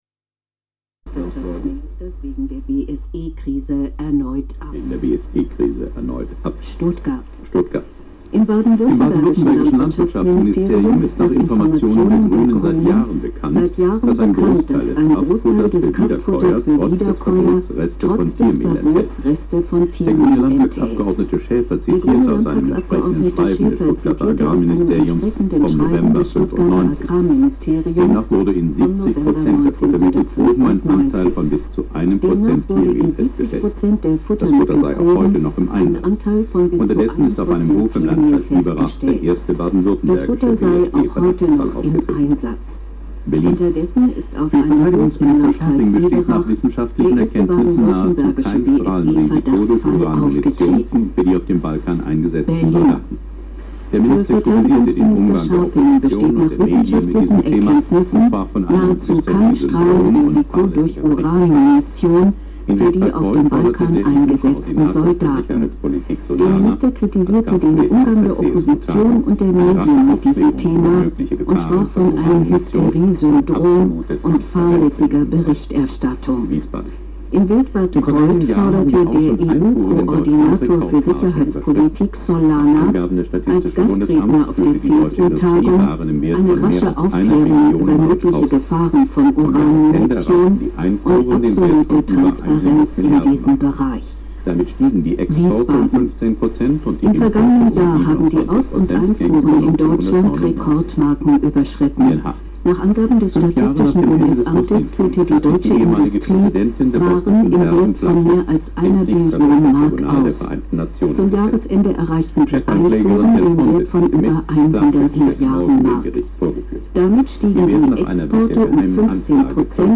Abbildung zweier sich überlagernder Radiosender mit fast gleichem Nachrichtentext mit Mikrophonen OKMII auf Minidisk.
Nachrichten.mp3